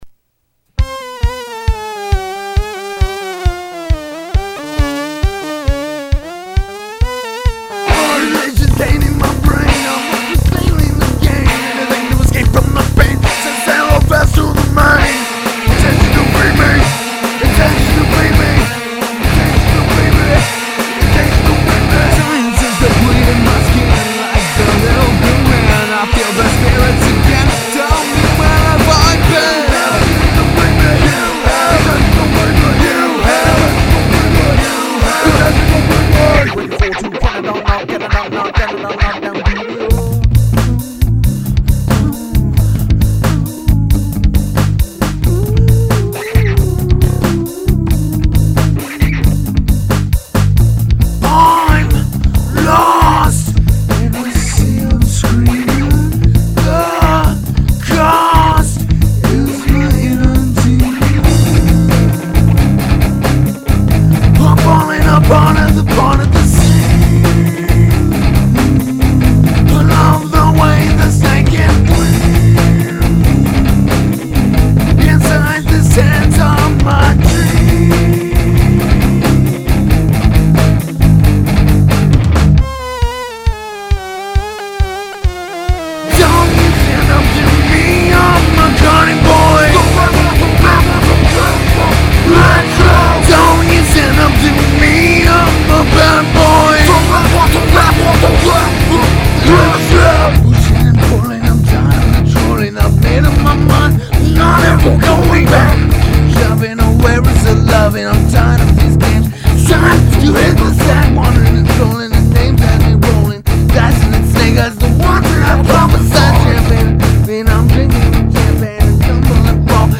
Rock
I dont really like the circus tune thing...
The circus theme music is a little out of context.